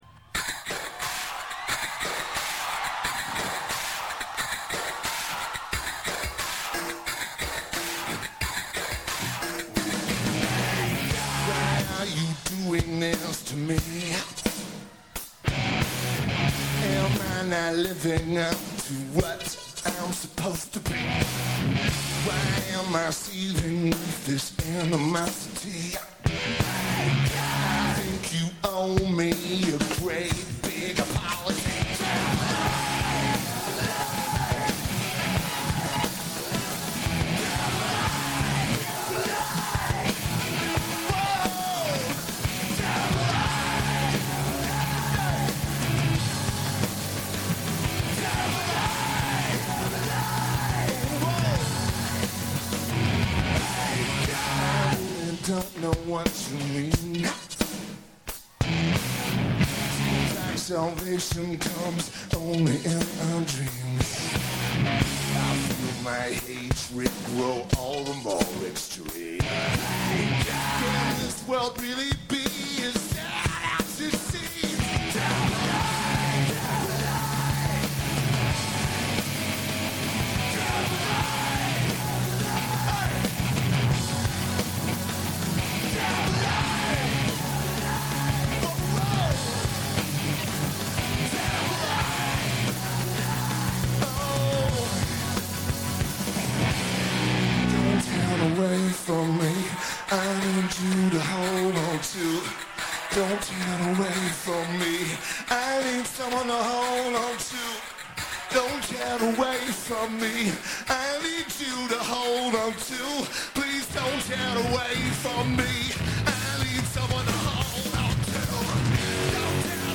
Bonnaroo Music Festival
Lineage: Audio - AUD (ALD -> Sony TCD-D8)